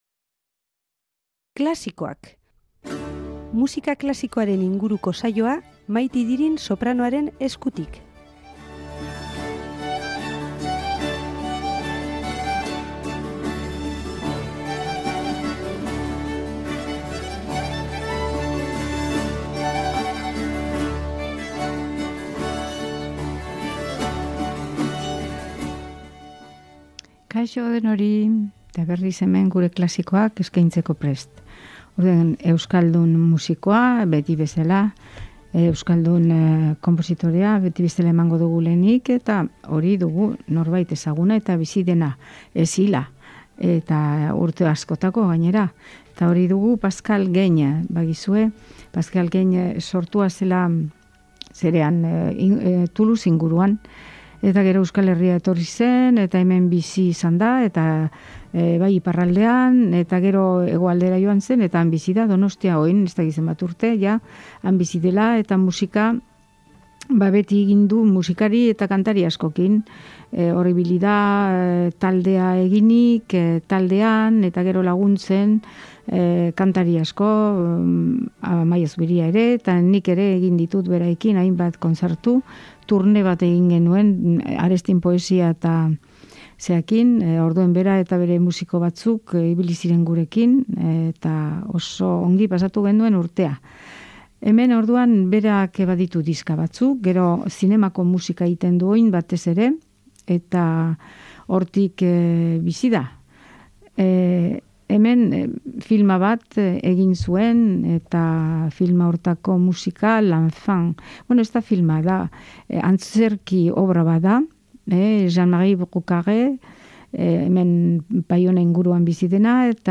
musika klasikoaren saioa